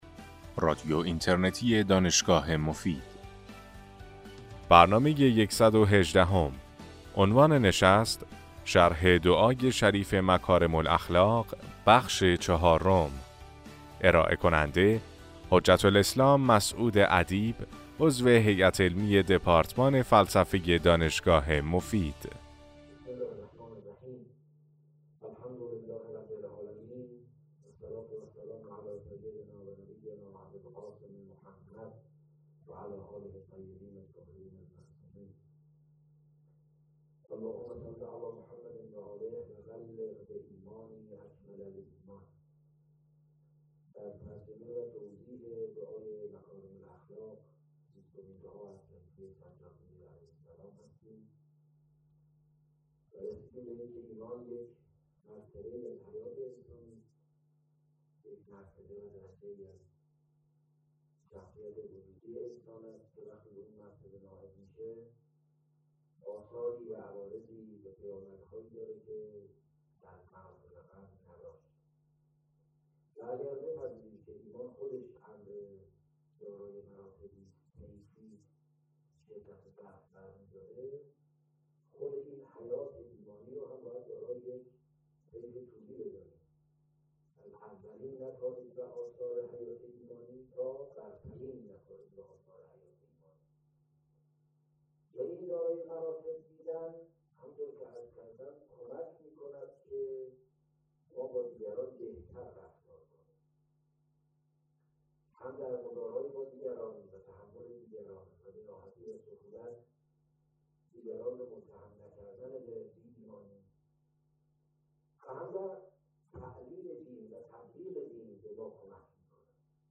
سلسله سخنرانی